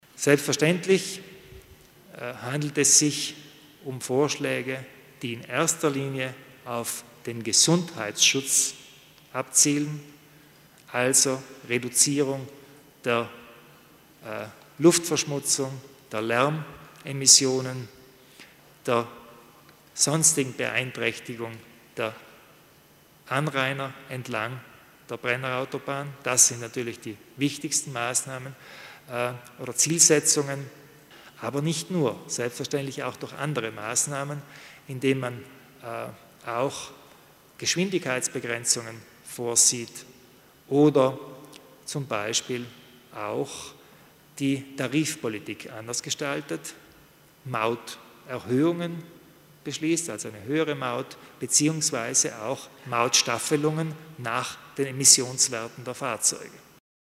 Landeshauptmann Kompatscher erläutert die Neuigkeiten in Sachen Konzession der Brennerautobahn